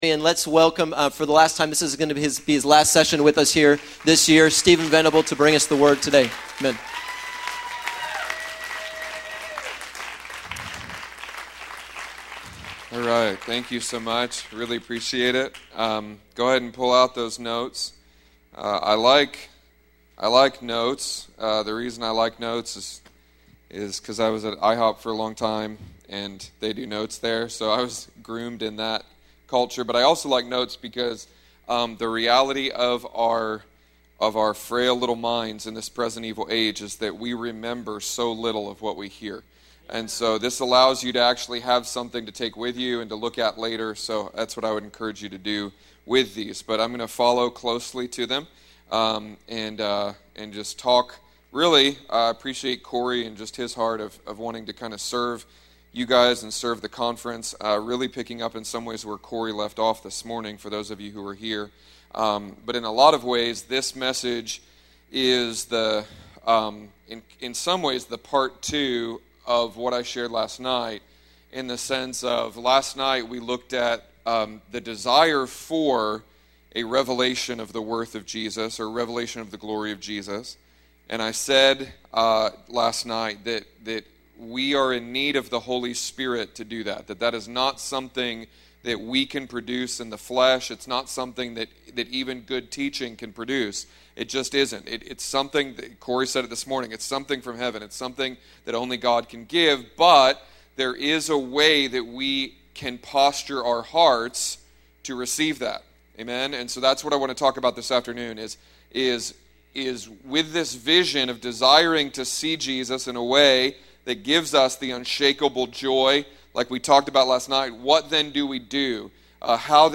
ServicePotter's House of Prayer